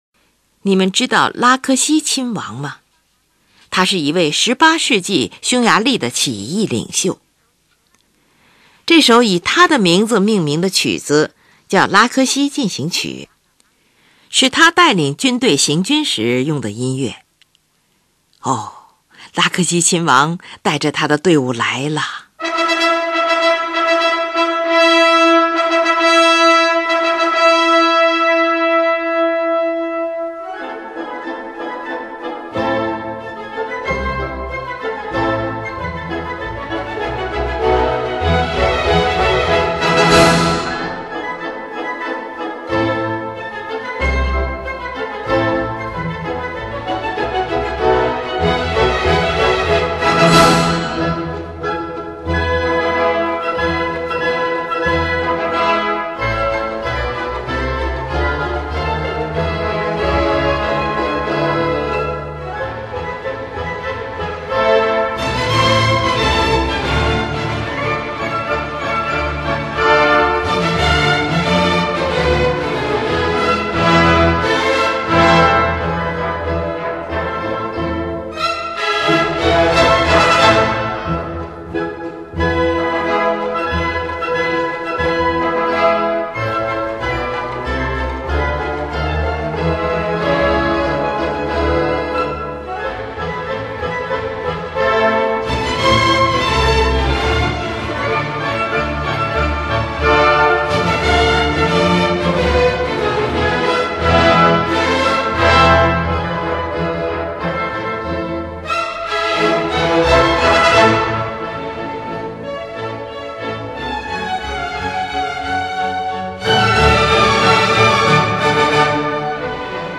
进行曲（March 英文）用于伴随步伐前进的音乐。
多用偶数拍子。
最后，在定音鼓长击的伴奏声中结束了全曲。